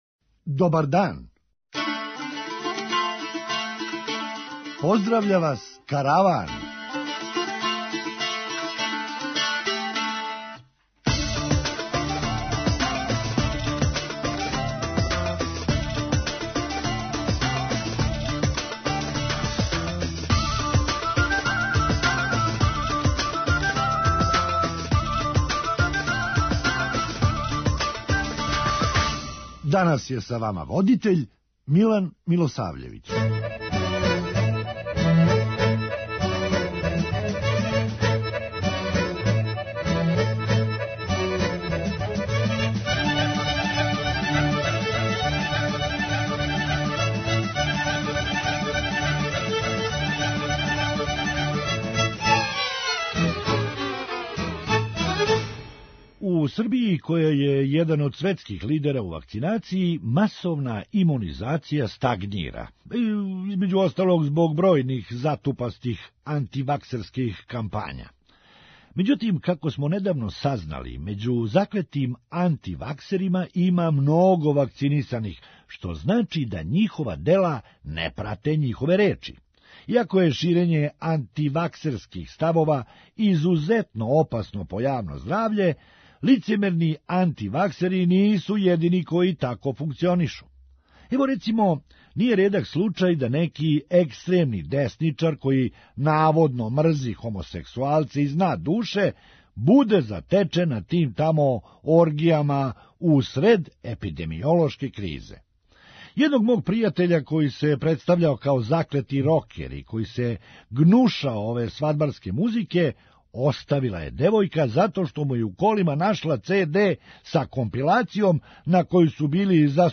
Хумористичка емисија
Па, несташица хране вероватно не, али с таквим ценама прети нам несташица пара. преузми : 8.99 MB Караван Autor: Забавна редакција Радио Бeограда 1 Караван се креће ка својој дестинацији већ више од 50 година, увек добро натоварен актуелним хумором и изворним народним песмама.